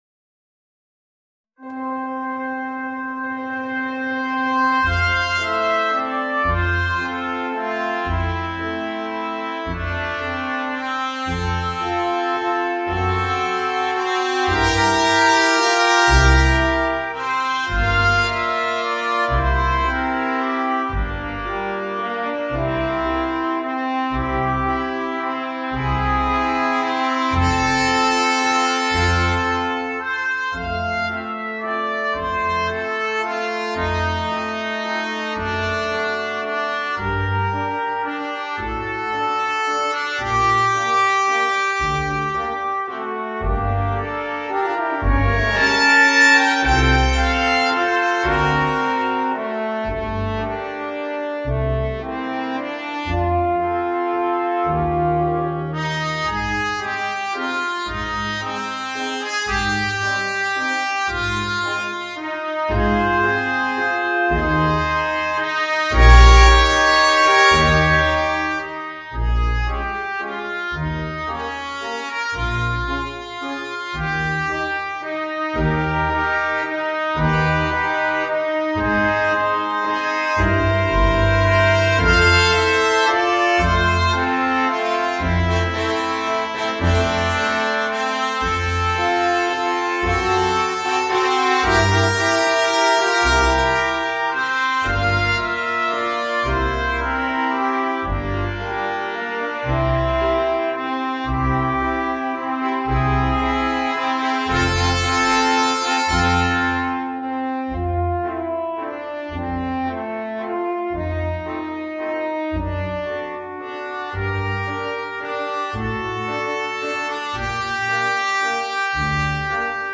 Voicing: 2 Trumpets, Trombone and Tuba